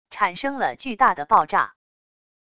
产生了巨大的爆炸wav音频